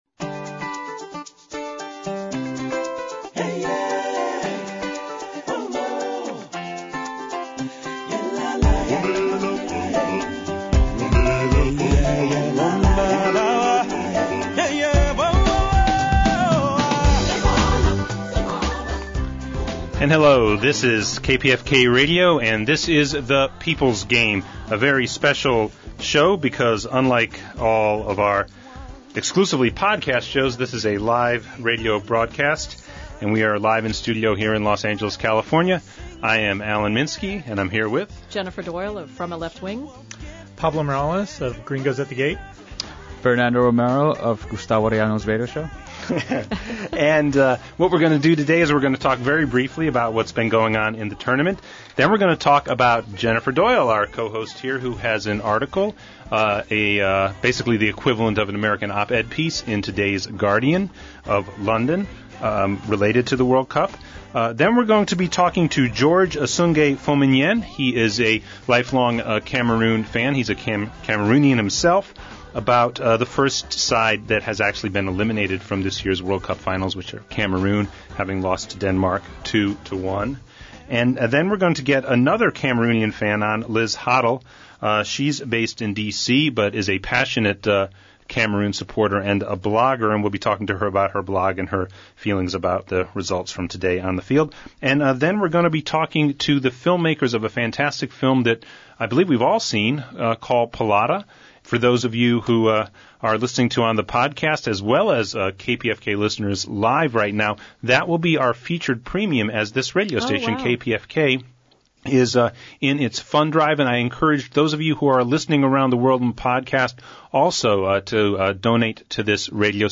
Today’s show was live-to-air on our home station, KPFK Radio Los Angeles.